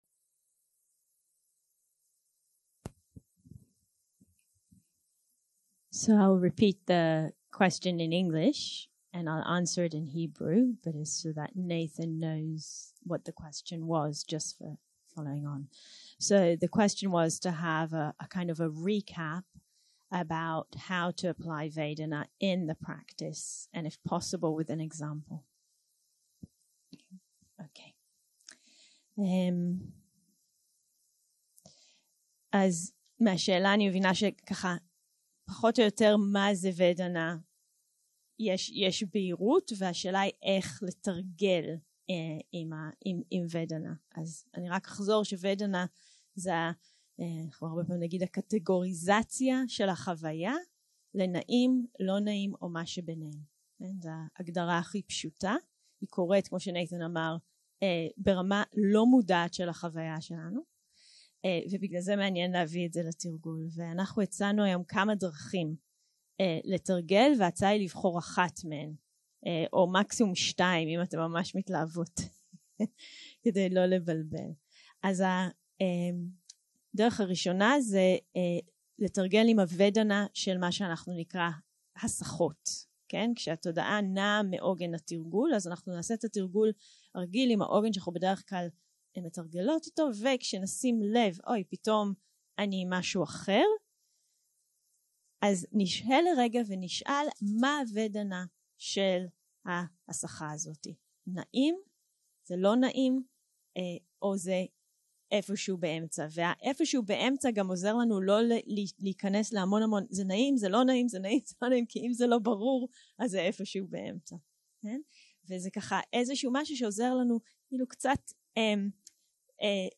Dharma type: Questions and Answers שפת ההקלטה